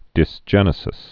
(dĭs-jĕnĭ-sĭs)